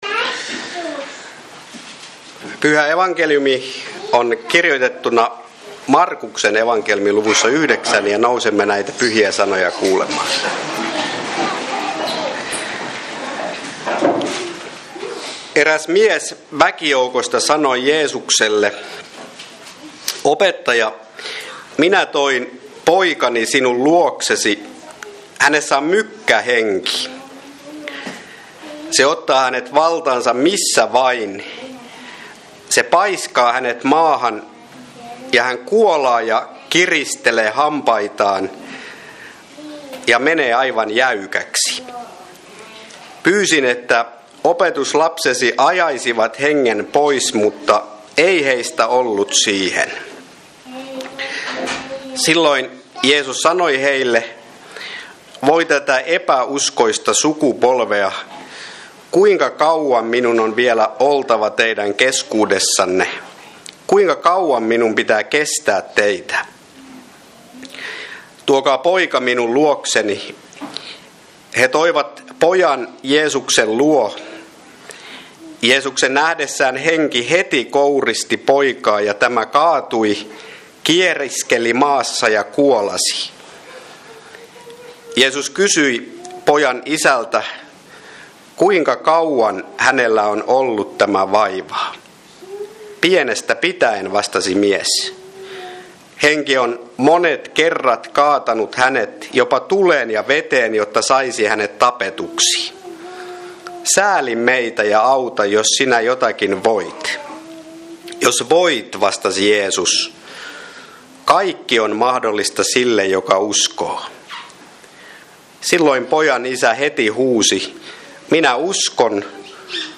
Kokoelmat: Seinäjoen Hyvän Paimenen kappelin saarnat